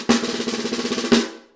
snare.mp3